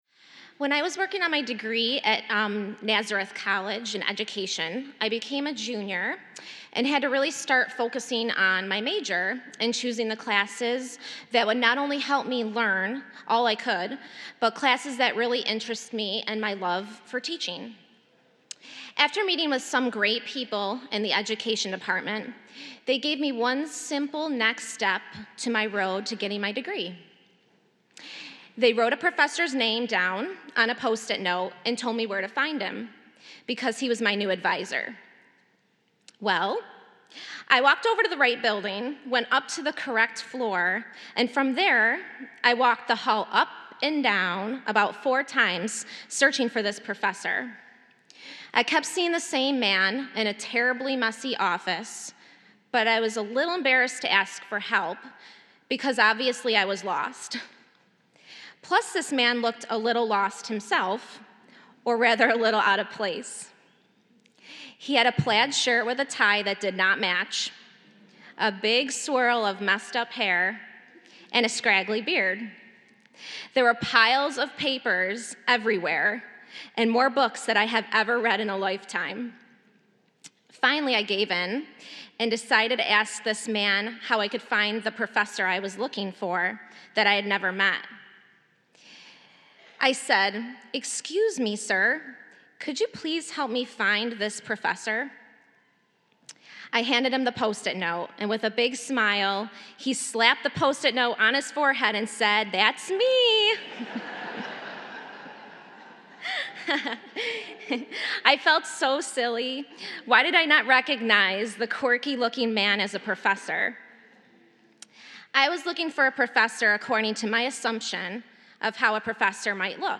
We celebrate our children and another successful year of Religious Education.
homily transcript